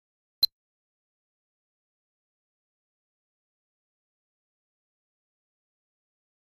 Misc. Sports Elements; Electronic Stopwatch On / Off Beep.